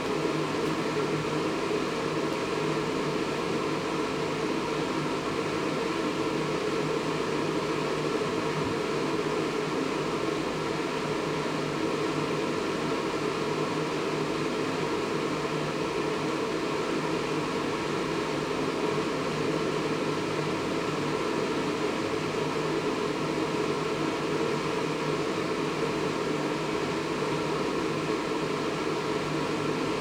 The following recordings are of Fan Coil Units in air conditioning units.
Noise levels without the Attenuator:
FCU-no-attenuator.mp3